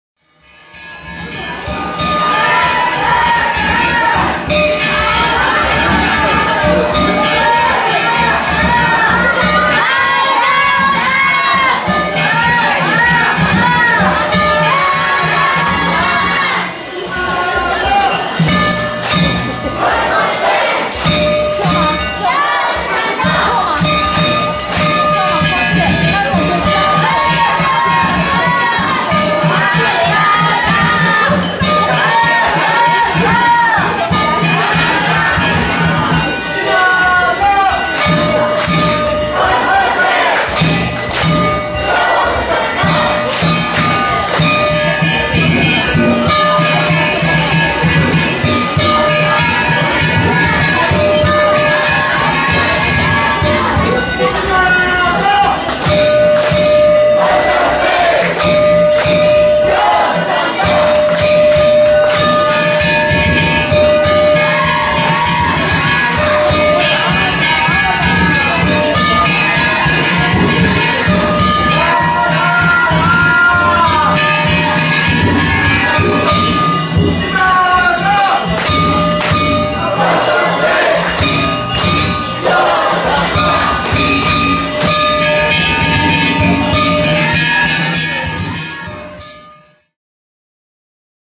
午後4時15分過ぎ、今里新道筋商店街を曳行する大今里地車
祝儀御礼の手打ちが続きます。
手打ちの囃子に重なるように道中囃子も聞こえます。
商店街内は手打ちが続きます。
子供たちの声が商店街に響きます。